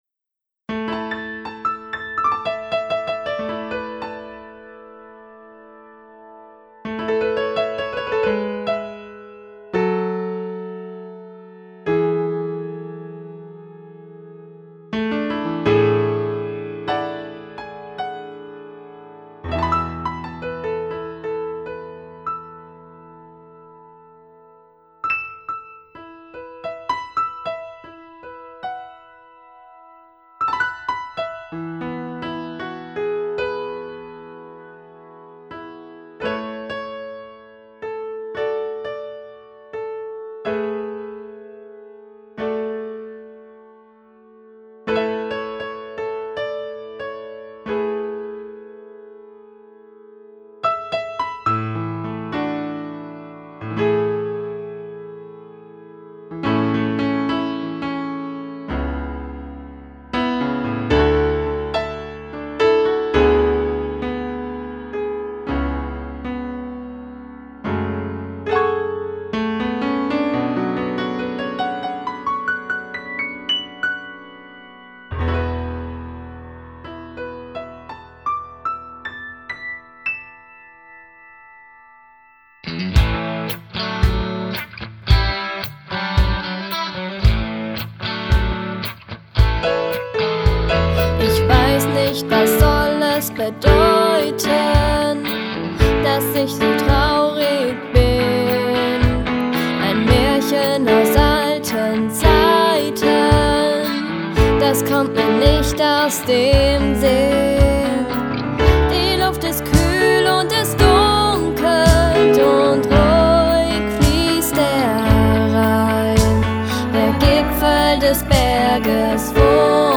gefühlvolle Pianopart